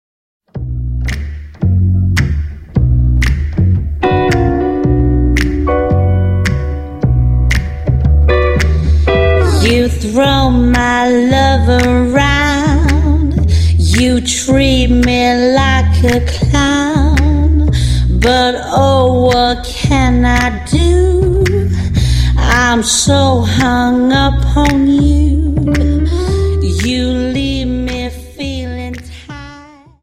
Dance: Slowfox 29